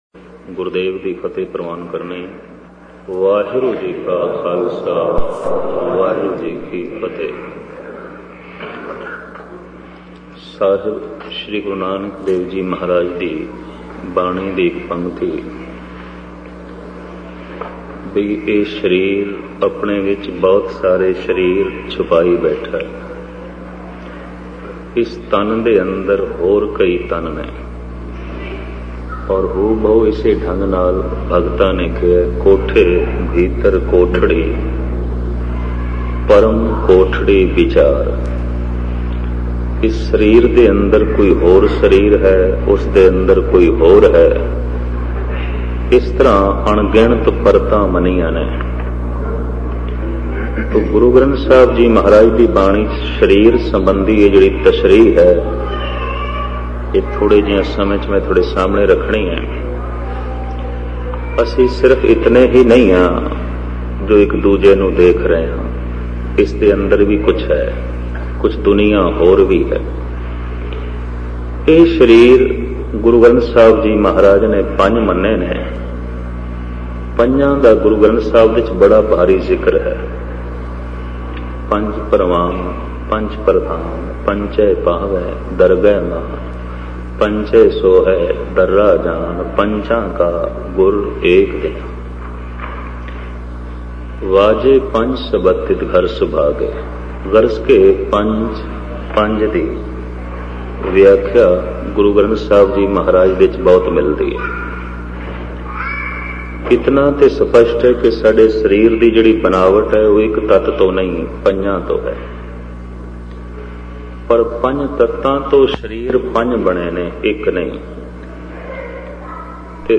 Katha
Album: Sarir De Andar Panj Sarir Genre: Gurmat Vichar